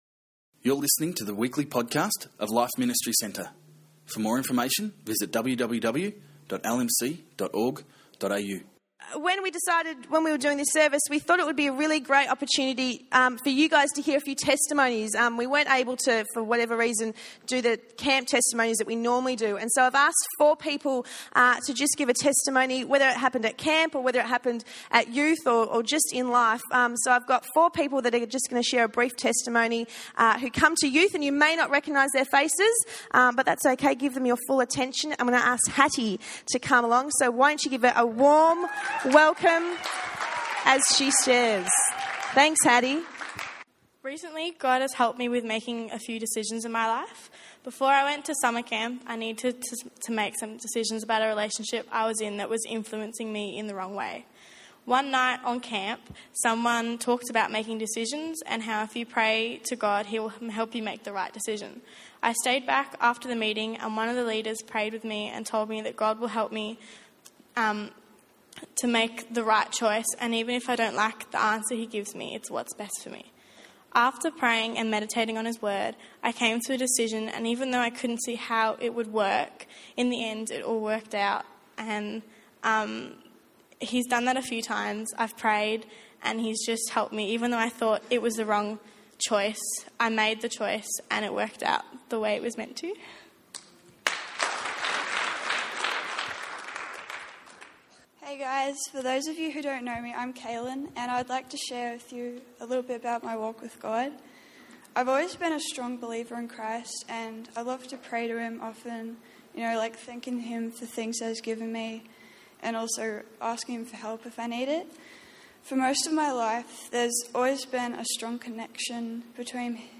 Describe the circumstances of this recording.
Frontline Youth took over the service on Sunday night. We were blessed to hear some great testimonies from young people whose lives have been changed by God through the youth group.